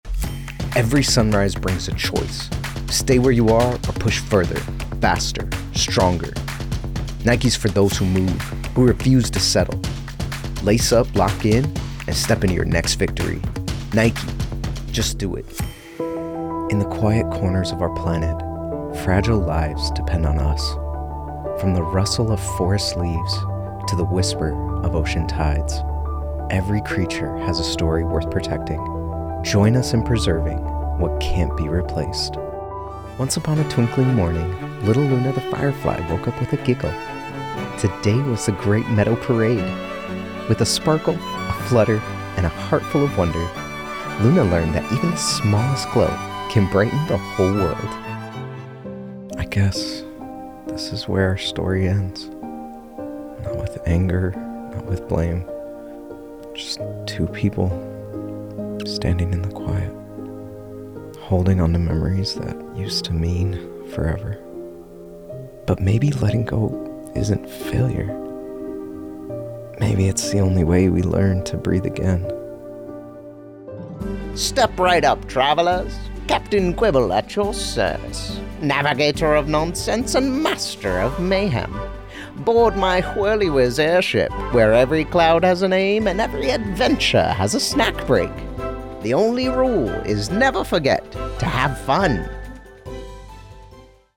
Demos
English - Western U.S. English
Young Adult
Middle Aged
VO Demo Reel 2025.mp3